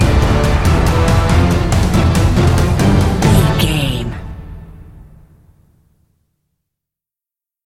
Fast paced
In-crescendo
Aeolian/Minor
strings
horns
percussion
electric guitar
orchestral hybrid
dubstep
aggressive
energetic
intense
synth effects
wobbles
driving drum beat